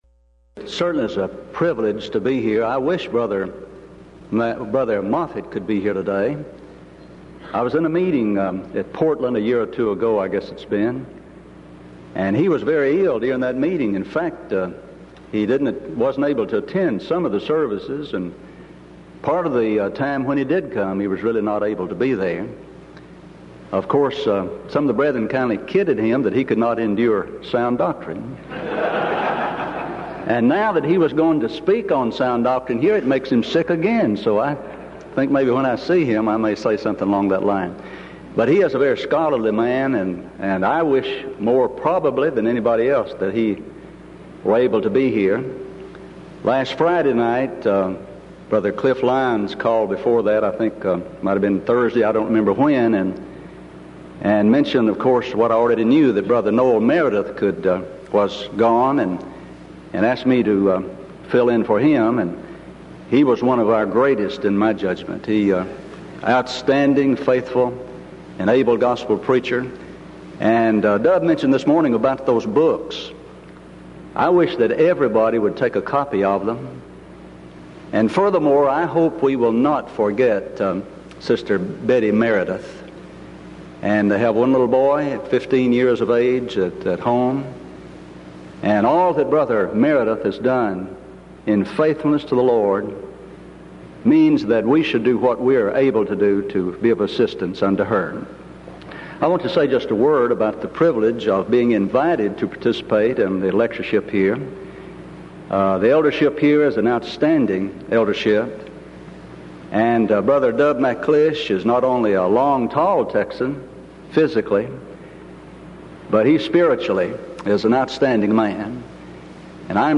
Title: DISCUSSION FORUM: Should Denomination Baptism Be Accepted By The Lord's Church?
Event: 1992 Denton Lectures